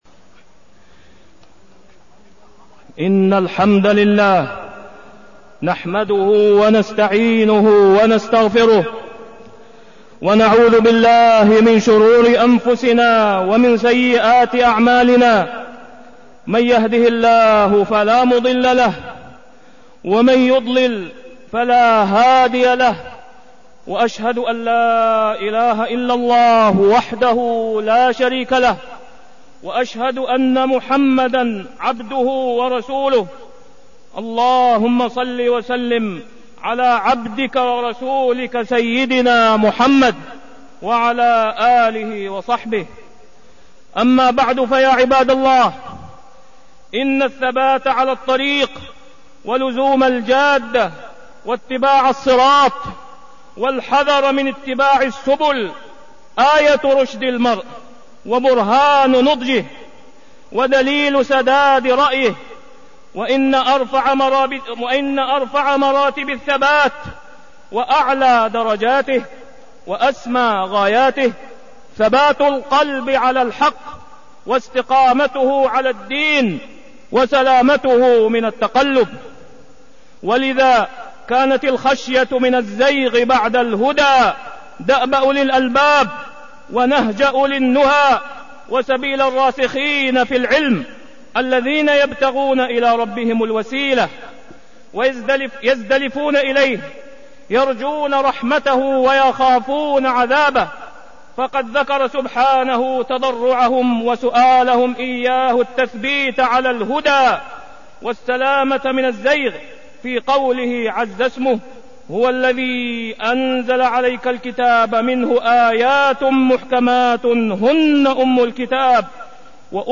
تاريخ النشر ٢٨ رجب ١٤١٨ هـ المكان: المسجد الحرام الشيخ: فضيلة الشيخ د. أسامة بن عبدالله خياط فضيلة الشيخ د. أسامة بن عبدالله خياط الثبات على الدين